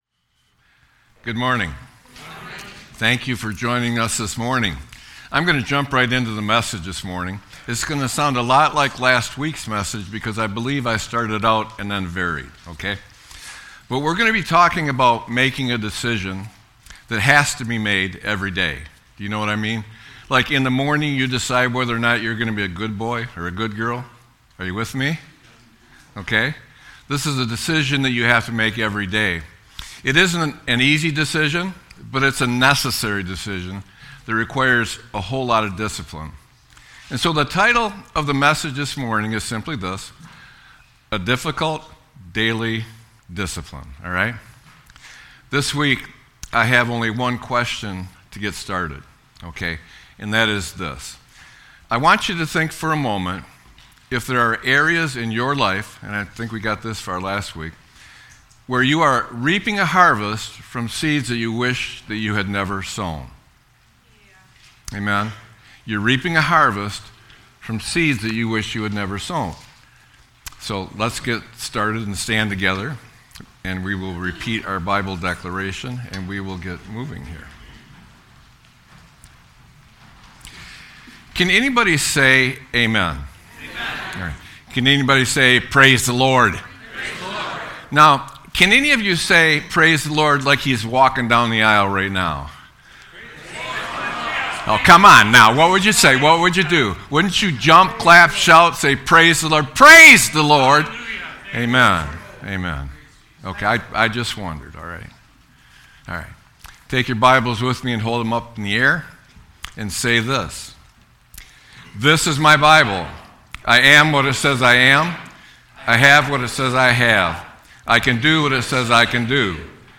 Sermon-1-11-26.mp3